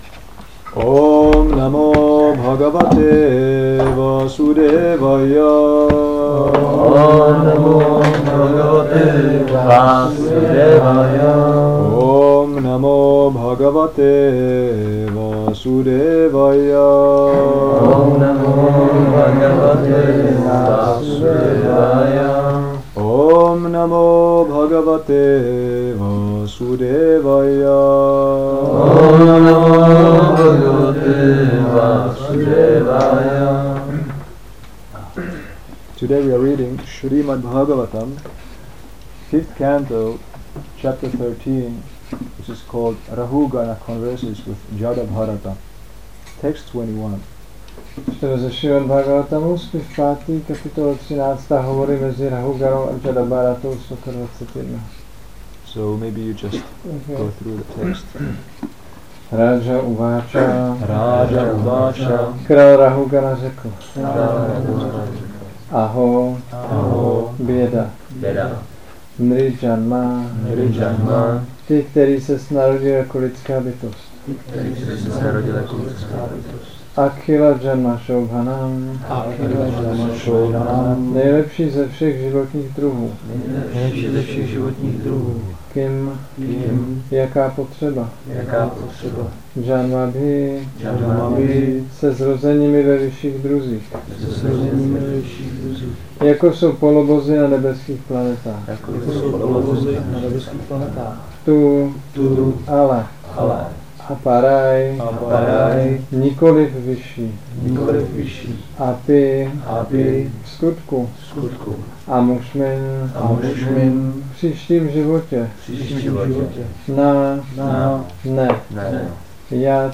Přednáška SB-5.13.21 – Šrí Šrí Nitái Navadvípačandra mandir